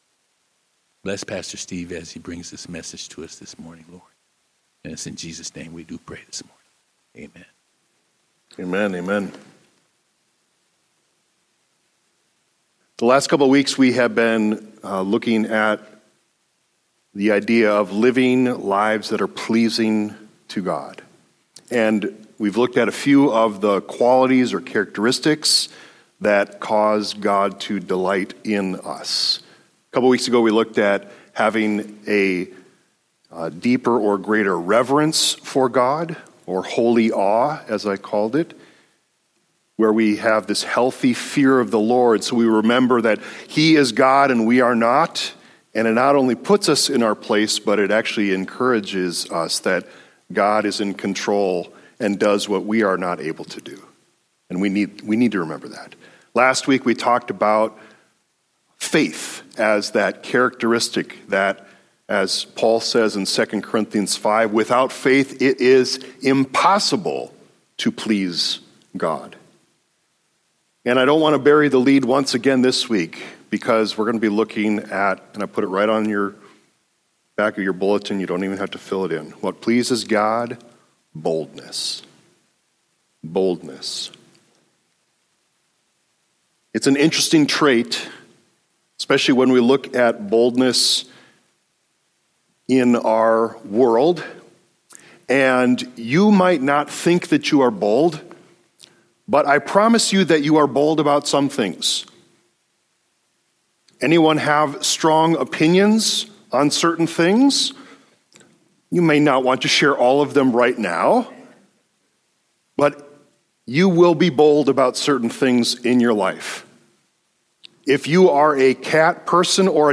Download Posted in sermons